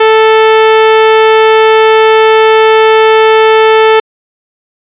TestTone_24b.wav